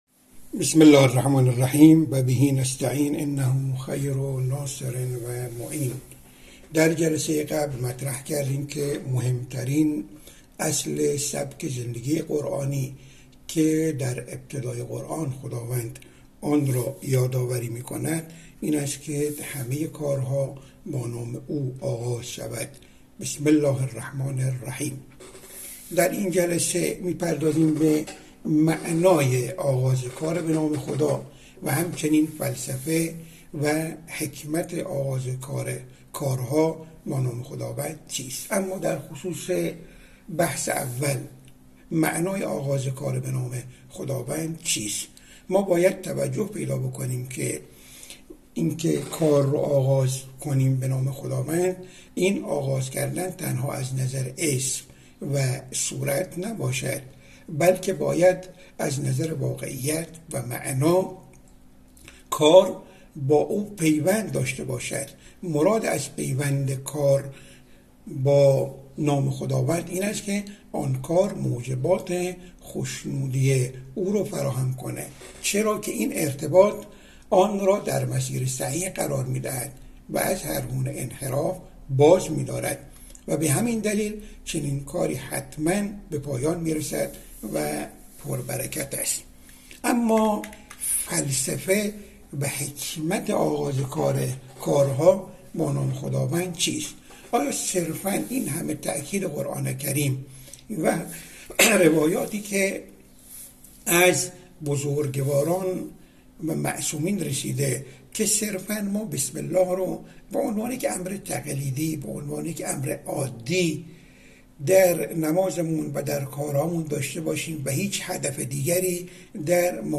وی در بخش پنجم این گفت‌وگو اظهار کرد: گفتیم اولین اصل سوره حمد، آغاز کارها به نام خداست.